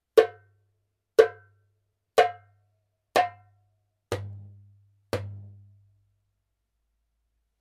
Djembe shell made in Cote d'Ivoire
Wood : Iroko
シャープで瑞々しい高音、イロコ独特のくっきりとした中音のレスポンス、豊かな低音。
ジャンベ音